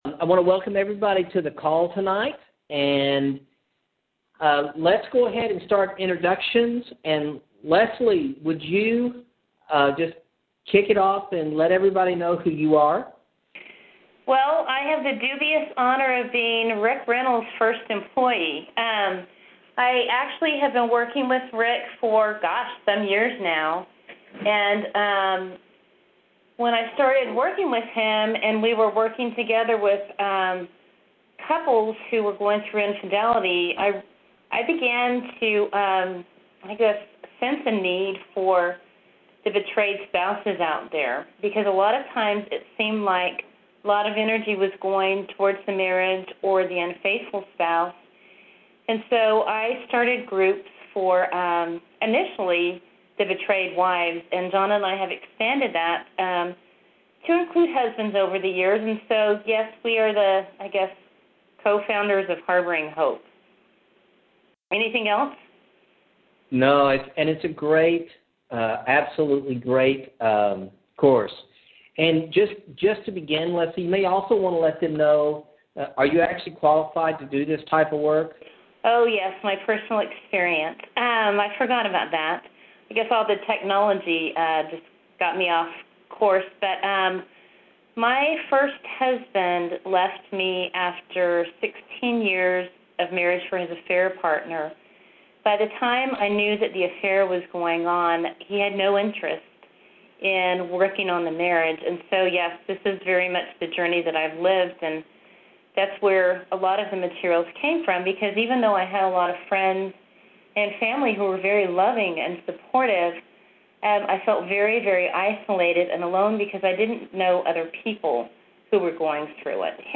Webinar with Harboring Hope Participants | Affair Recovery
Here is an interview with several participants from a past Harboring Hope course. If you in the midst of a struggle I believe you'll find their stories to be encouraging.